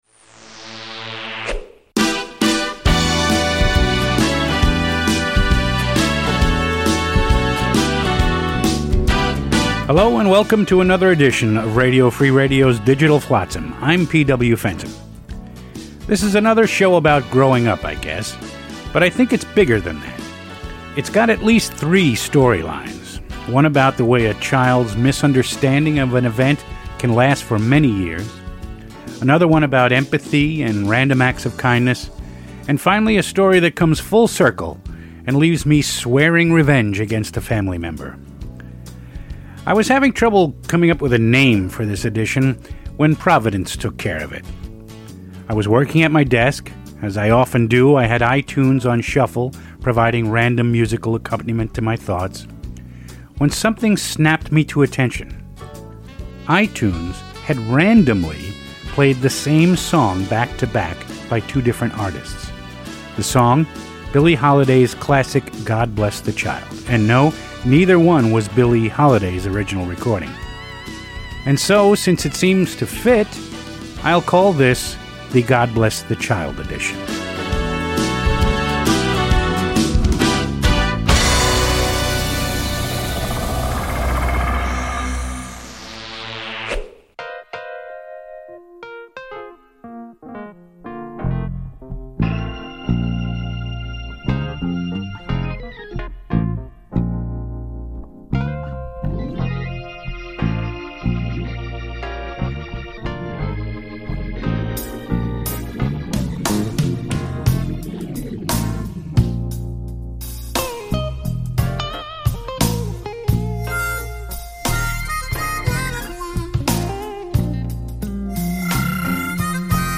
We are proud to offer these great spoken word pieces again.